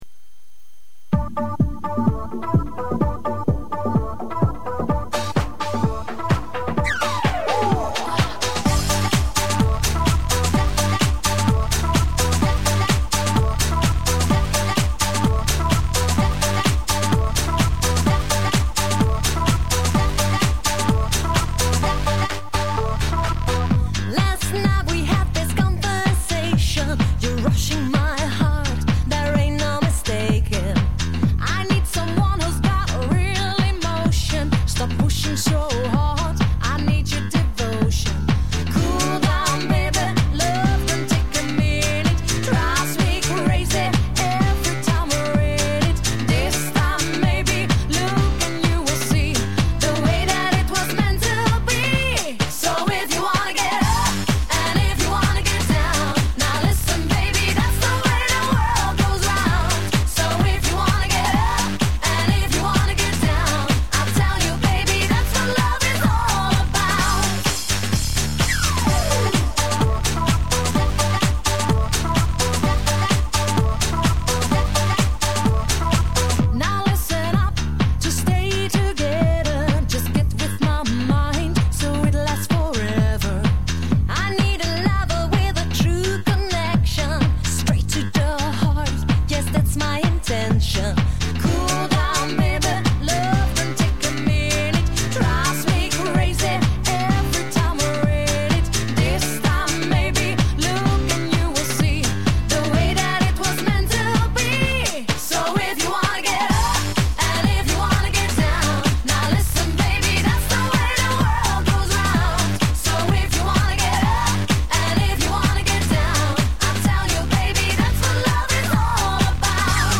drummer
accordeonist